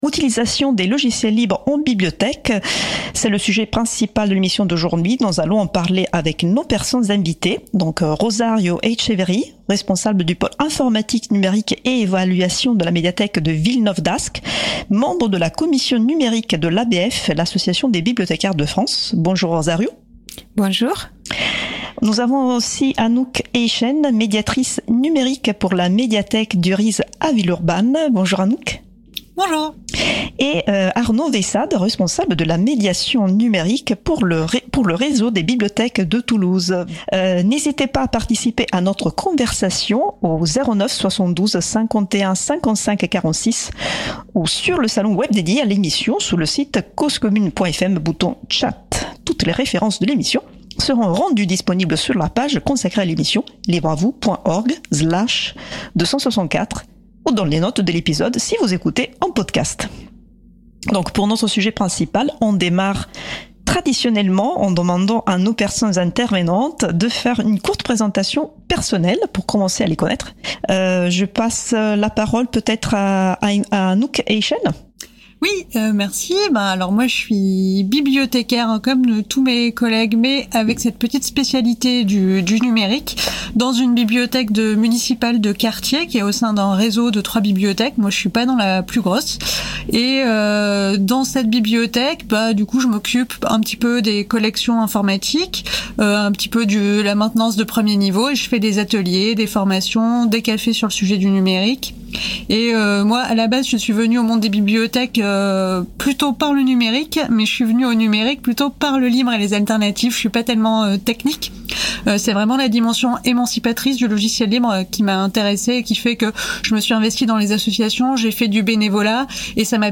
Libre à vous ! est l’émission de radio proposée par l’April sur la radio Cause Commune, « la voix des possibles ».